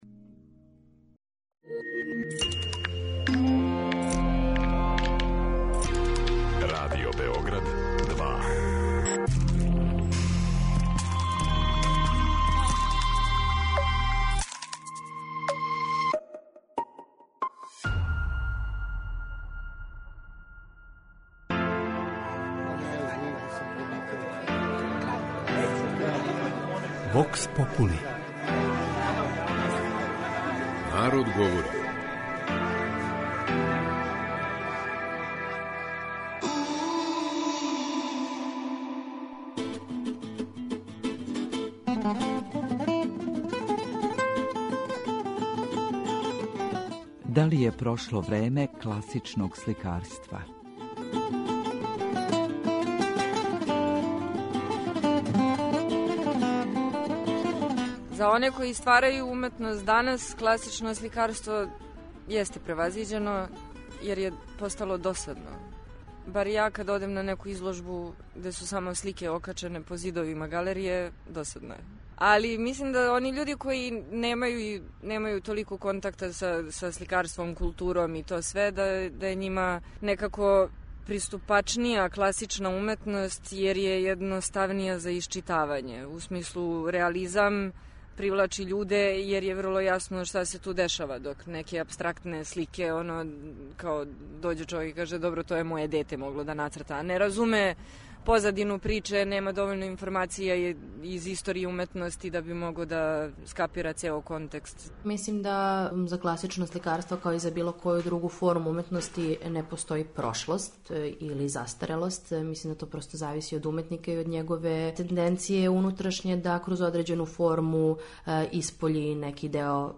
Вокс попули